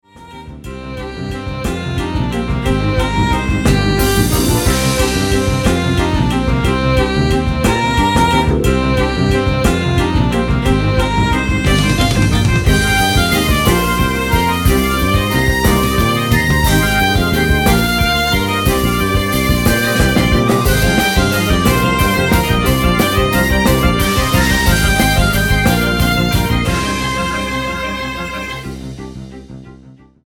スピーディーで爽快な変拍子祭を展開！